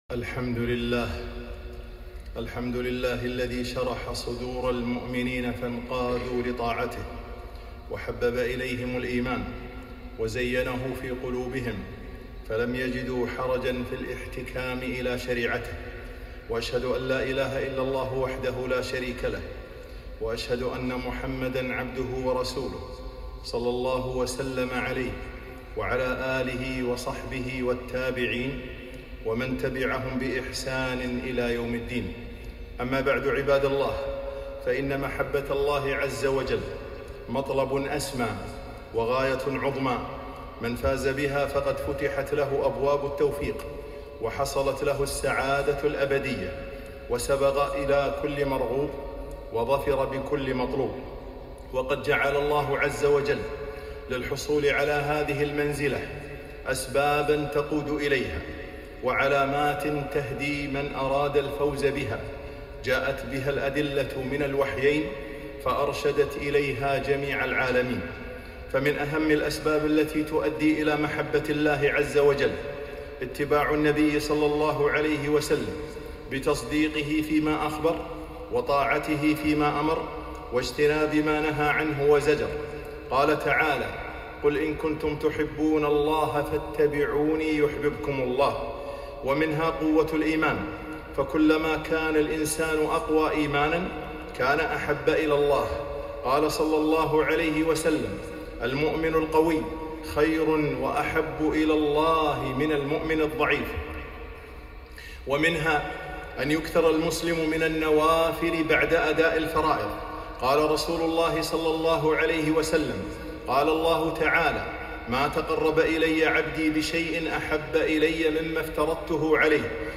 خطبة - الطريق الي محبة الله